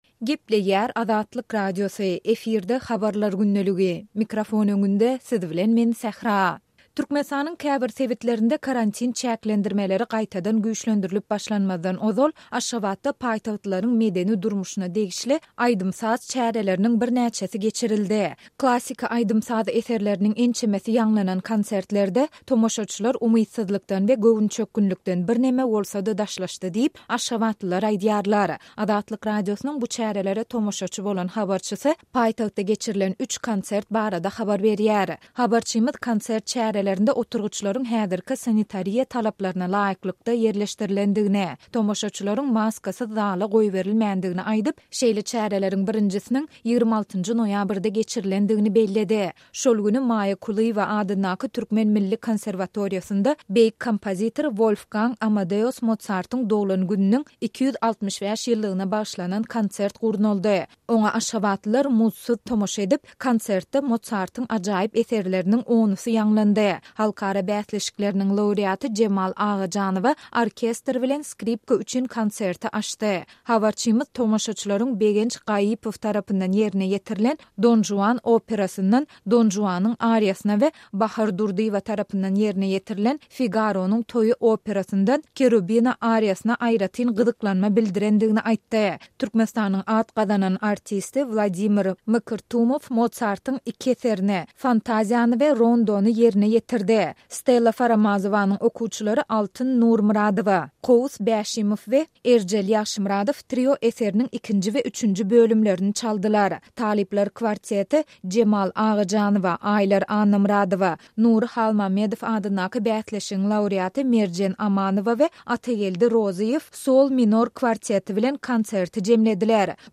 Azatlyk Radiosynyň bu çärelere tomaşaçy bolan habarçysy paýtagtda geçirilen üç konsert barada habar berýär.